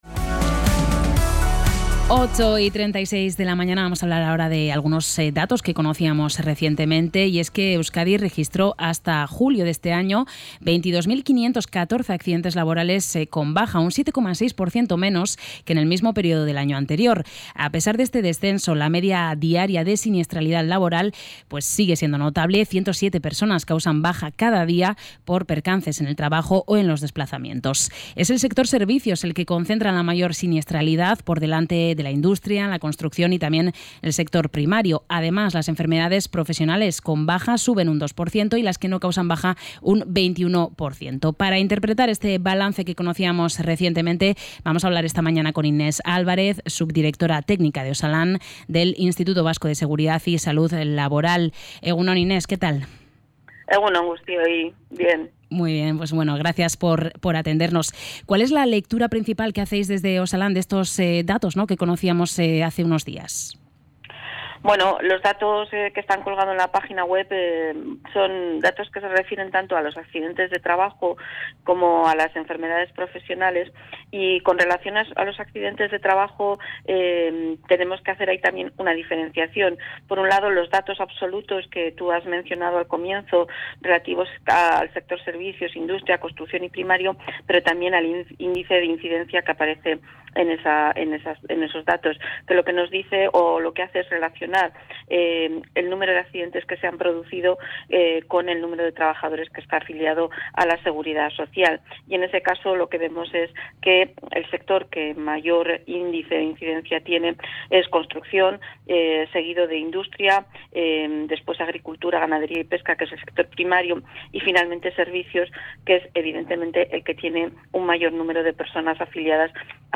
Entrevista-OSALAN.mp3